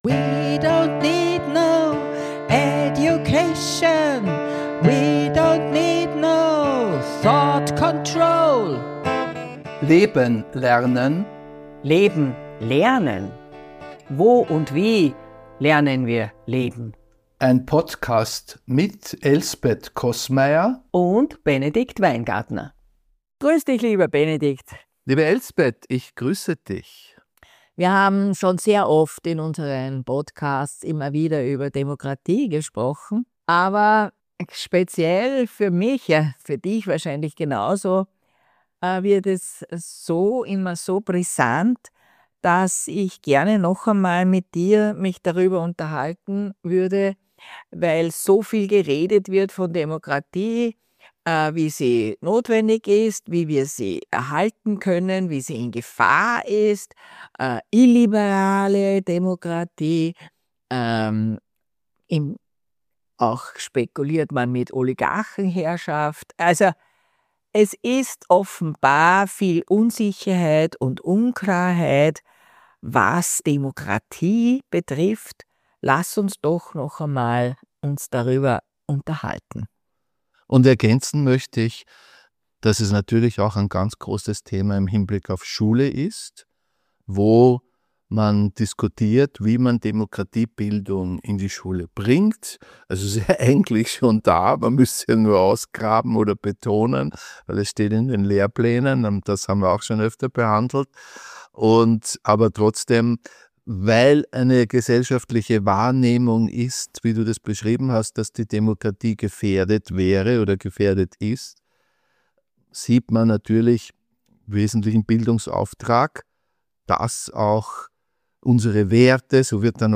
Mit ihren unterschiedlichen Zugängen führen sie kritisch und mit utopischen Gedanken im Hinterkopf einen Dialog.